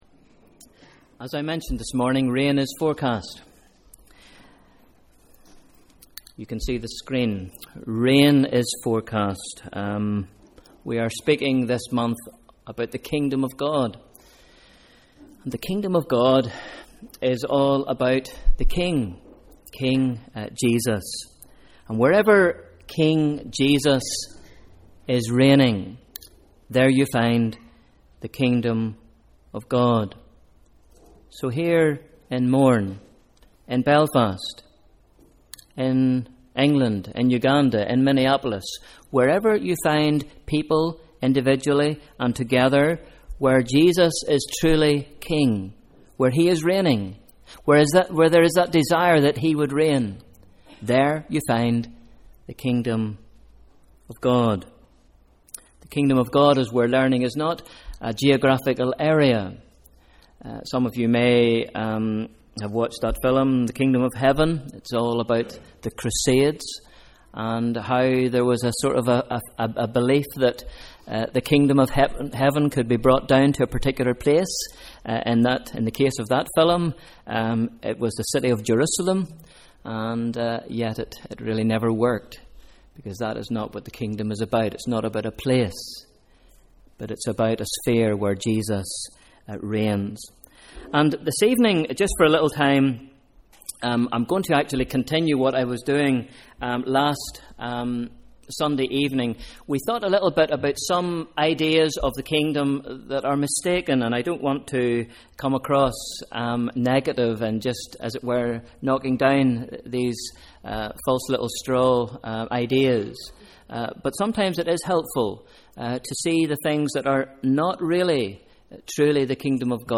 Evening Service: Sunday 14th July 2013